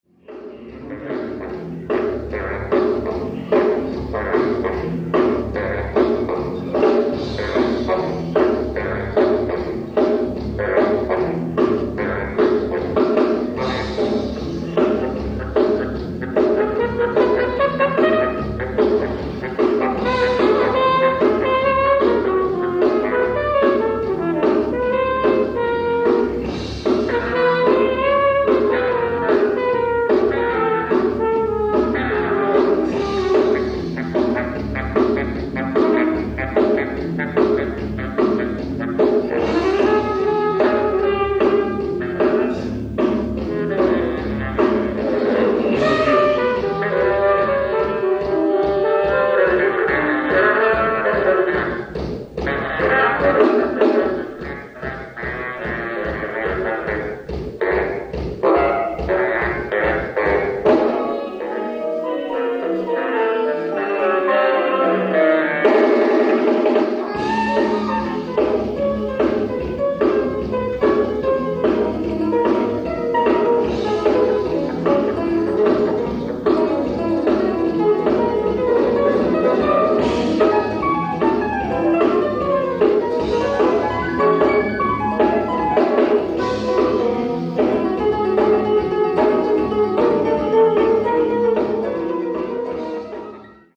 ライブ・アット・スメラルド劇場、ミラン、イタリア 03/11/1991
※試聴用に実際より音質を落としています。